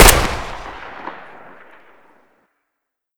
saiga_shoot.ogg